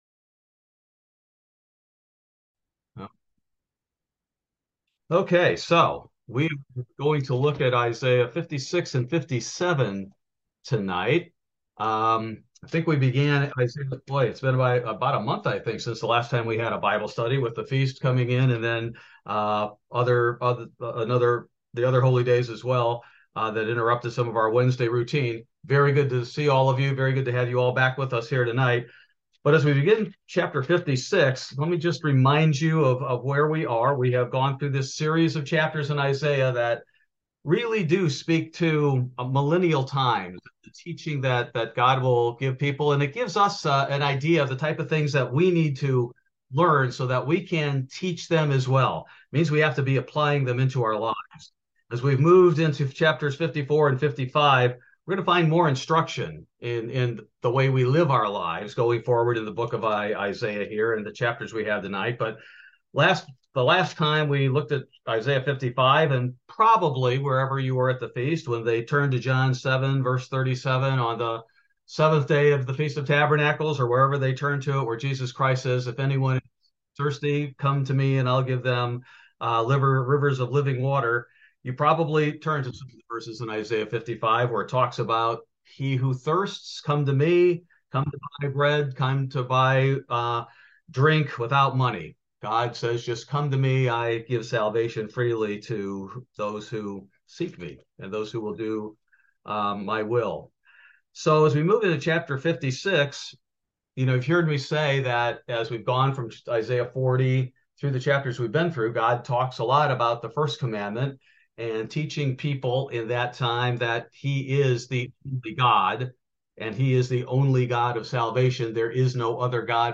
This verse by verse Bible Study focuses primarily on Isaiah 56: Blessed is He Who Keeps from Defiling the Sabbath.